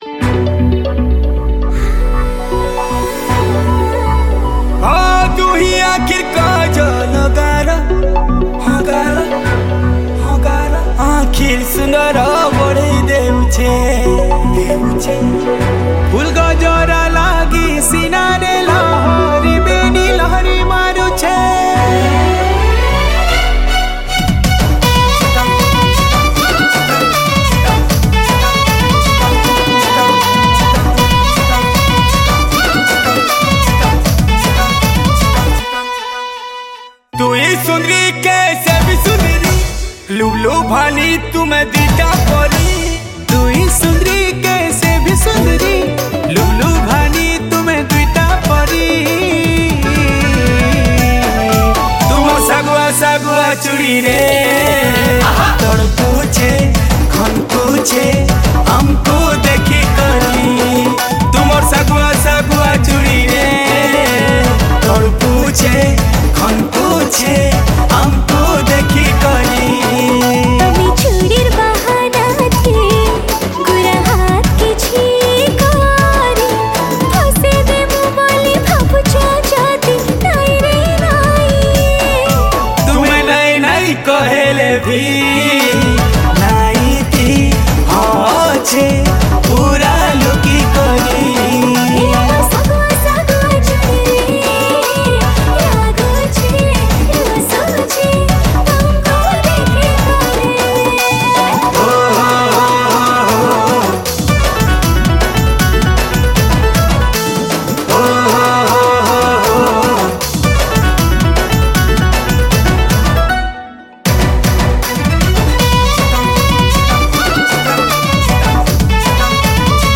Category: New Sambalpuri